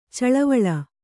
♪ caḷavaḷa